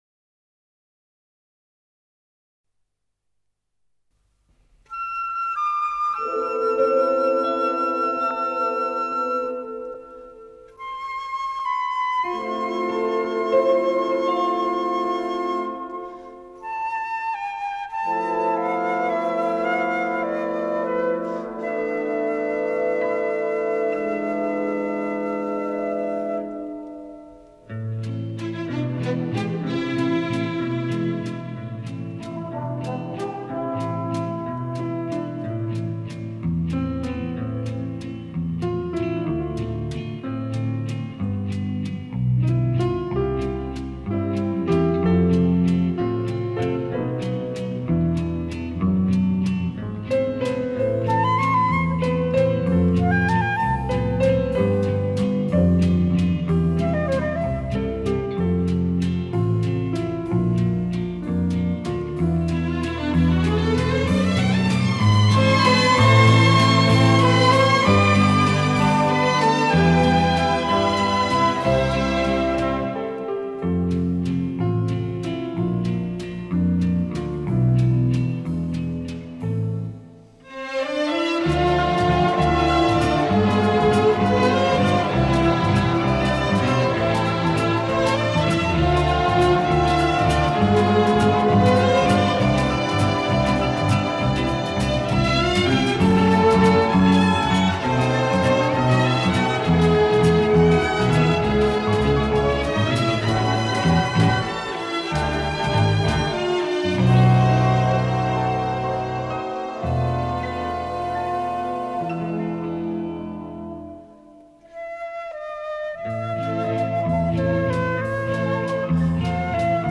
ИСПОЛНЯЕТ КОНЦЕРНЫЙ  ЭСТРАДНЫЙ ОРКЕСТР ЦТ И ВР